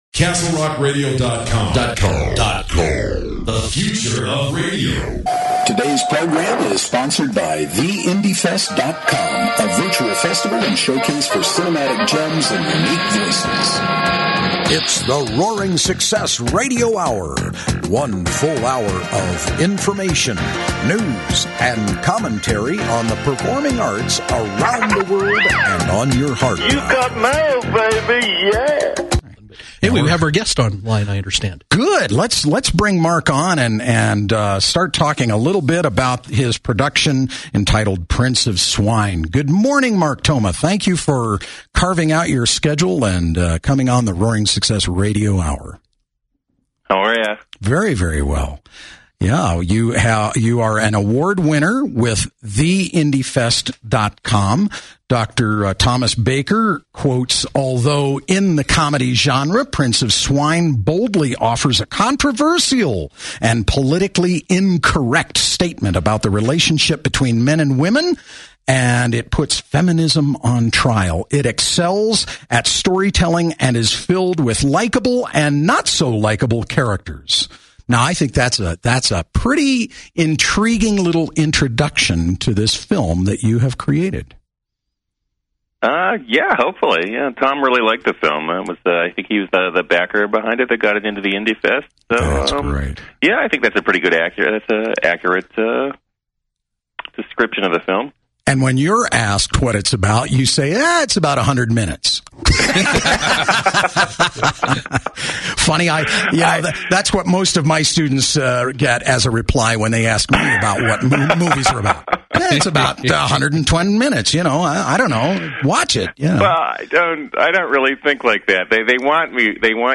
USC Interview